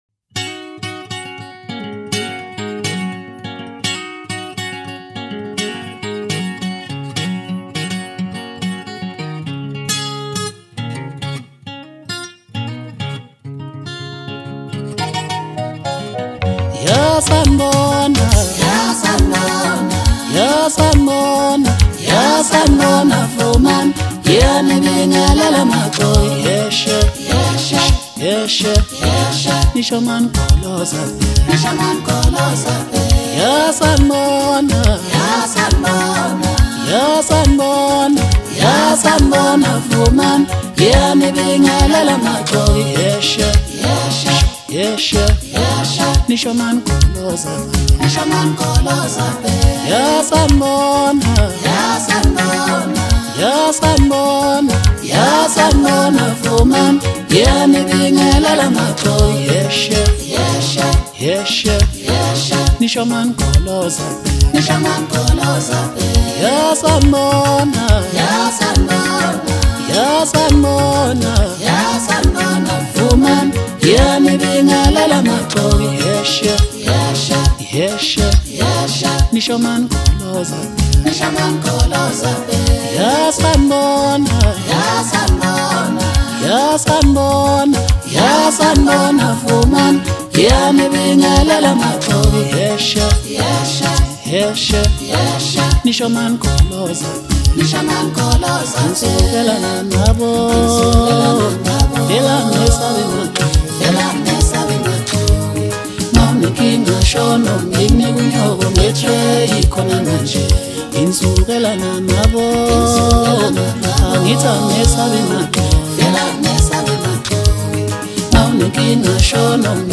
Home » Maskandi » DJ Mix » Hip Hop
Gifted vocalist